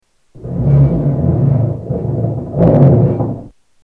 Muffled drilling